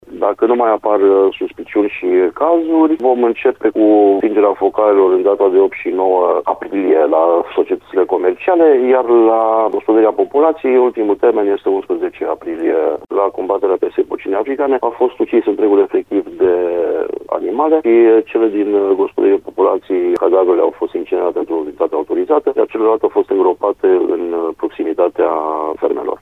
Într-o primă etapă, se anulează restricțiile în cazul societăților comerciale, ulterior cele pentru gospodăriile particulare, ecplică directorul DSVSA Timiș, Flavius Nicoară.